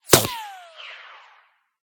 whine_6.ogg